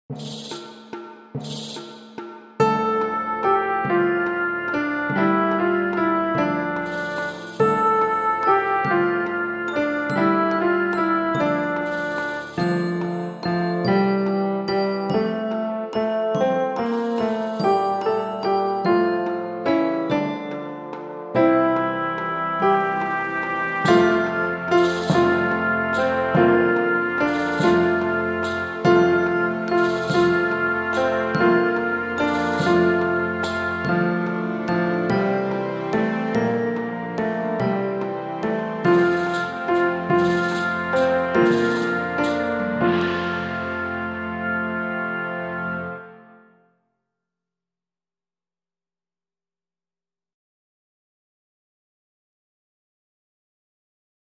Pr. Accomp